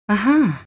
lara-croft-says-aha.mp3